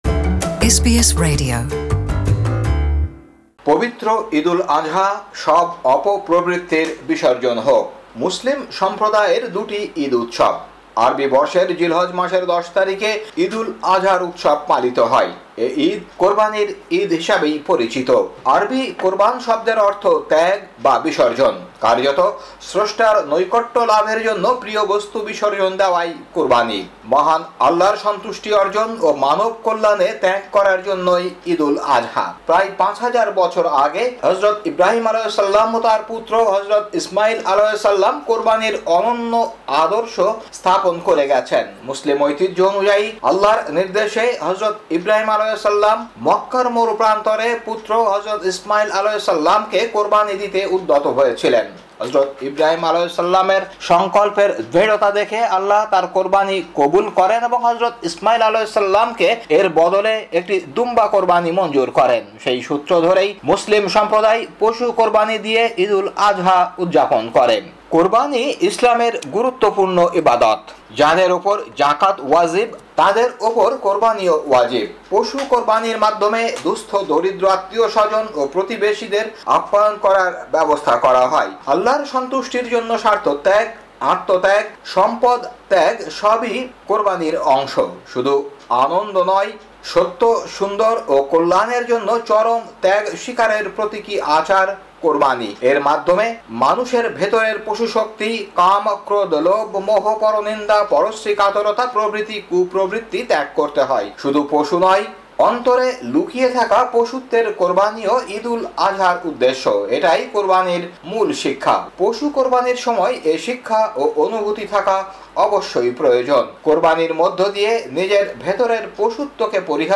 প্রতিবেদনটি শুনতে উপরের অডিও প্লেয়ারে ক্লিক করুন।